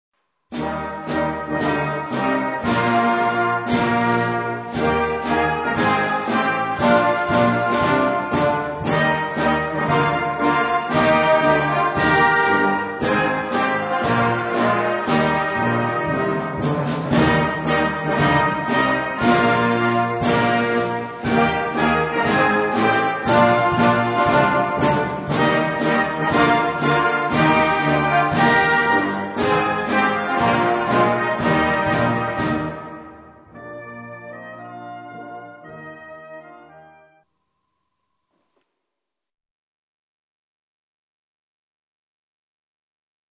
• Une suite facile mais fascinante en trois parties.